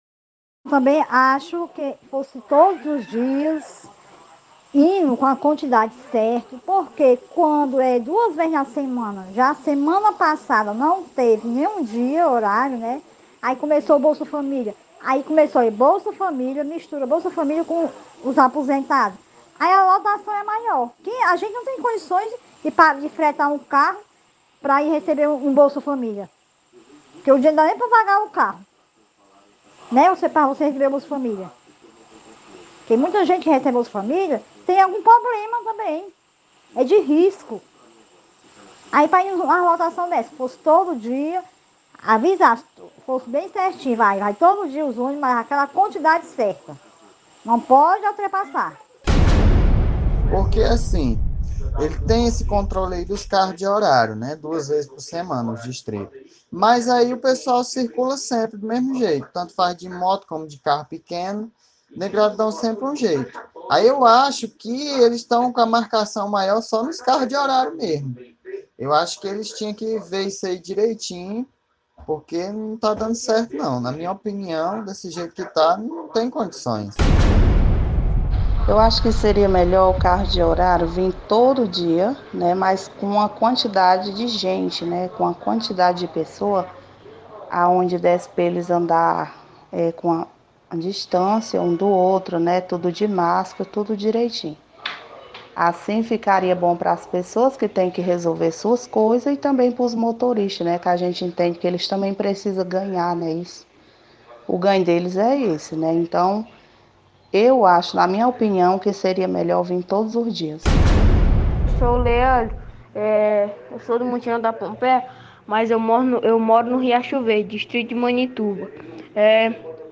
Em relatos feitos durante o programa SerTão Conta Mais, da SerTão TV e rádio Campo Maior AM 840, na manhã desta sexta-feira, 28, diversos populares falaram sobre a situação envolvendo os carros de horário nas barreiras sanitárias localizadas nas entradas da sede urbana de Quixeramobim, no Sertão Central. De acordo com as falas, os veículos vêm lotados por conta de ser somente dois dias para cada distrito.